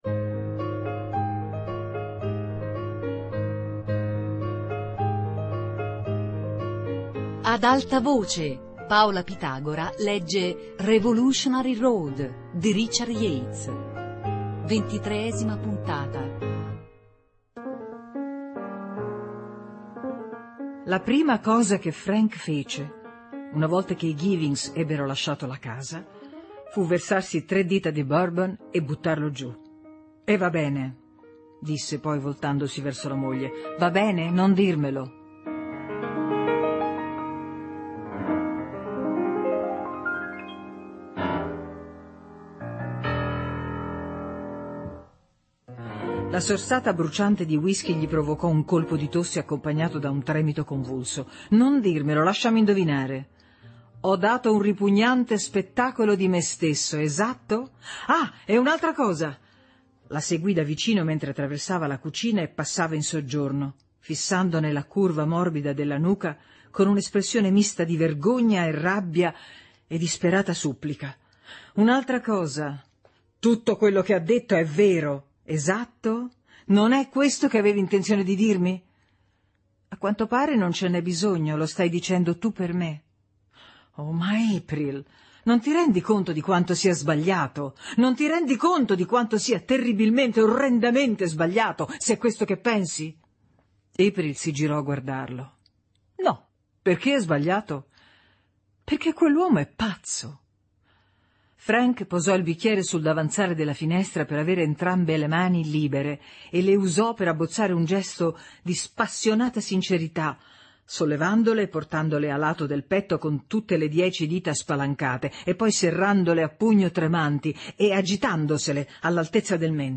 Revolutionary road - Lettura XXIII